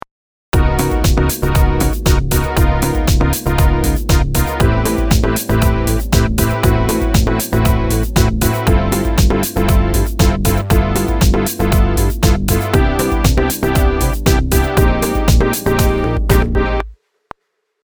エレピ、ギターの音で試してきましたが、今回はシンセブラスの音で試してみましょう。